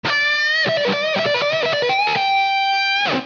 Gitarre Schnelles Solo klingelton kostenlos
Kategorien: Klassische